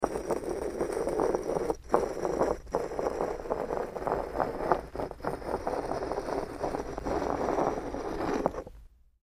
Scrape, Stone
StoneScrapesOnSmoo PE442201
Stone Scrapes; On Smooth Cement Slowly.